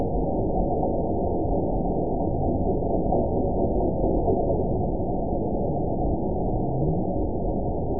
event 922136 date 12/27/24 time 05:36:13 GMT (11 months, 1 week ago) score 8.95 location TSS-AB04 detected by nrw target species NRW annotations +NRW Spectrogram: Frequency (kHz) vs. Time (s) audio not available .wav